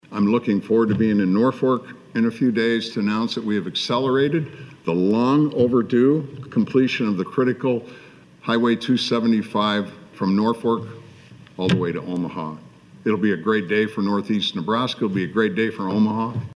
PILLEN ALSO TALKED ABOUT A MAJOR HIGHWAY PROJECT BEING COMPLETED IN EASTERN NEBRASKA: